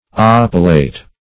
Search Result for " oppilate" : The Collaborative International Dictionary of English v.0.48: Oppilate \Op"pi*late\, v. t. [imp.